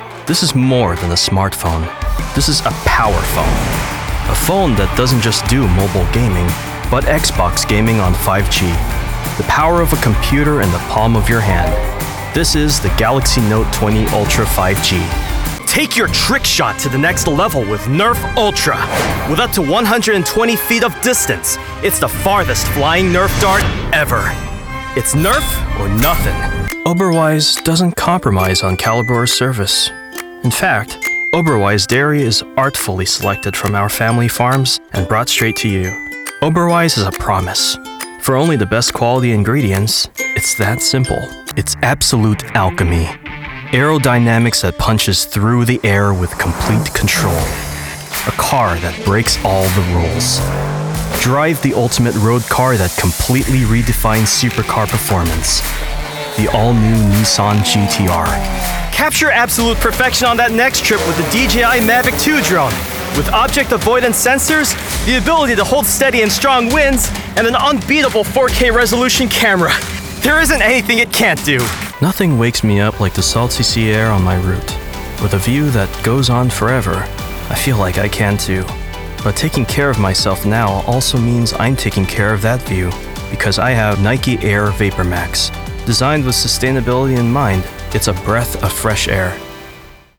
Teenager, Young Adult, Adult, Mature Adult
COMMERCIAL 💸
broadcast level home studio